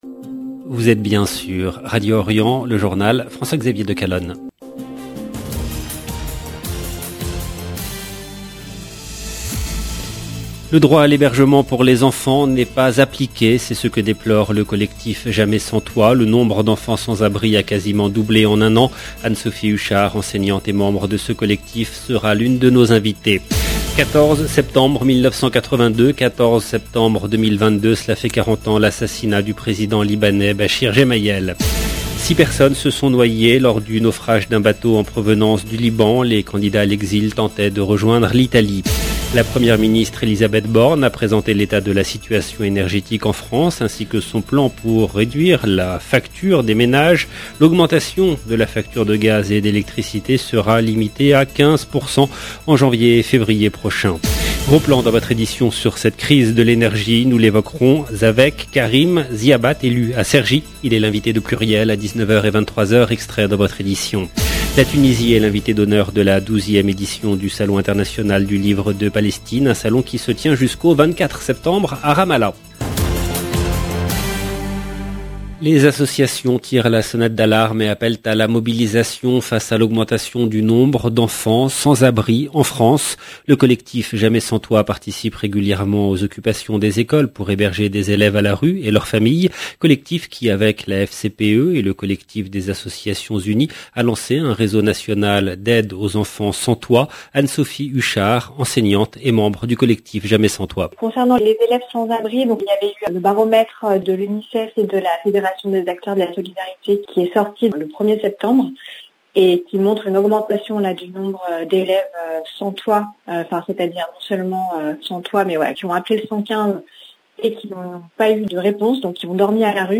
EDITION DU JOURNAL DU SOIR EN LANGUE FRANCAISE DU 14/9/2022